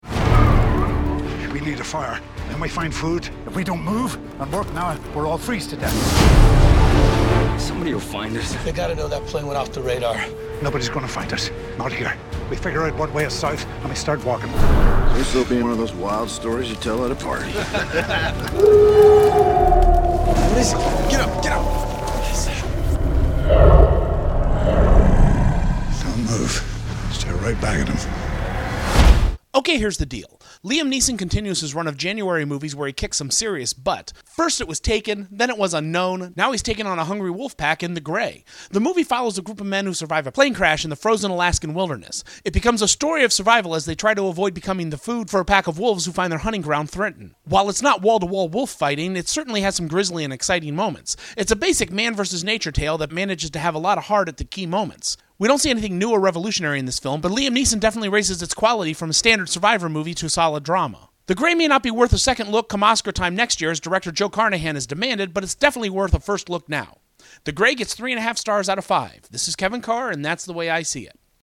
Movie Review: ‘The Grey’